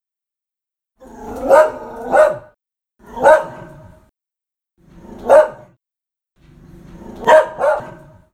dog-dataset
dogs_0049.wav